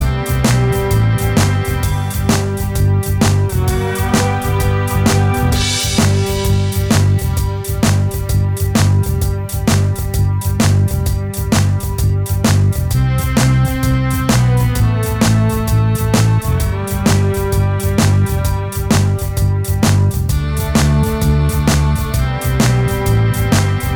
Minus Guitars Glam Rock 4:08 Buy £1.50